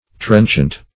Trenchant \Trench"ant\, a. [OF. trenchant, F. tranchant, p. pr.